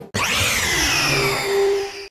Archivo:Grito de Necrozma Alas del Alba.ogg